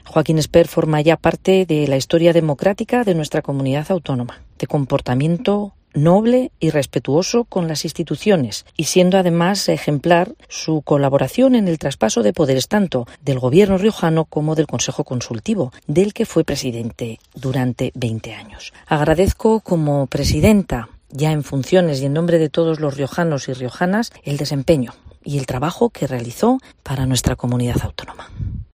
Escucha aquí las palabras de Concha Andreu, presidenta del Gobierno de La Rioja en funciones, tras el fallecimiento del expresidente Joaquín Espert: "Era una persona noble"